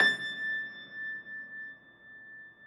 53g-pno21-A4.wav